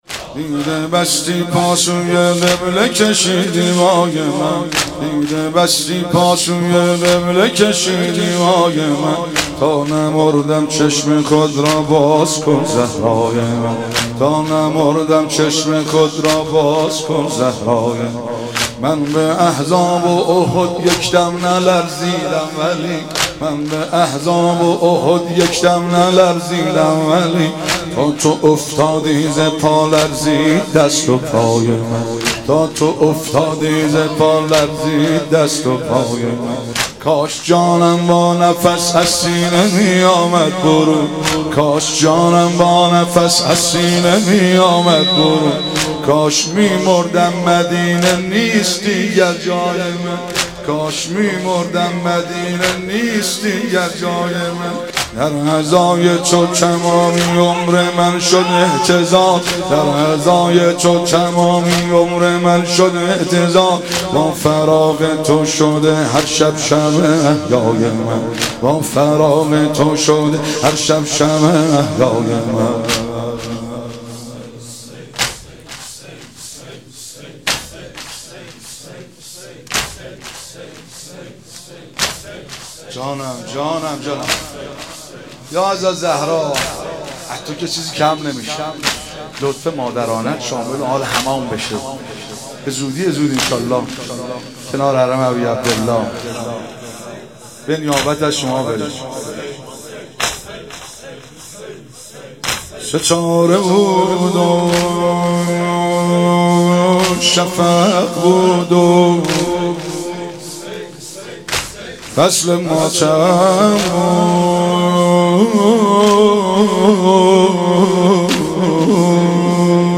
شب دوم فاطمیه 95 - واحد - تا نمردم چشم خود را باز کن زهرای من
فاطمیه